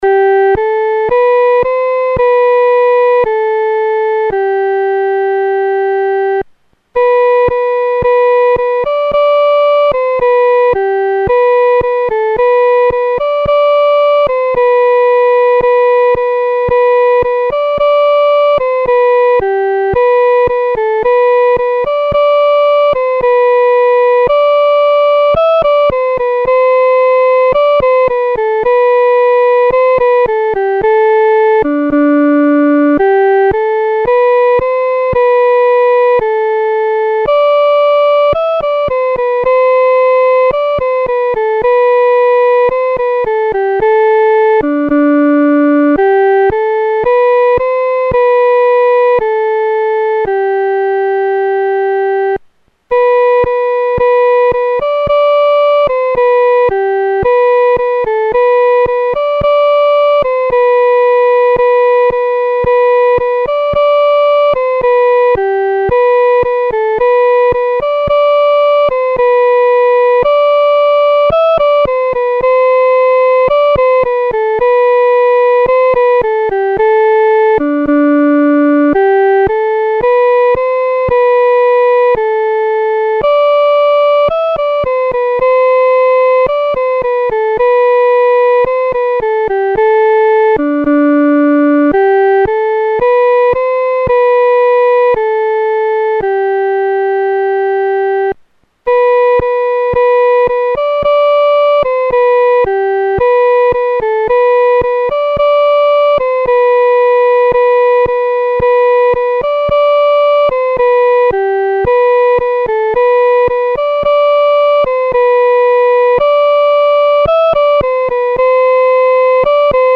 独奏（第一声）
Traditional French Carol,1855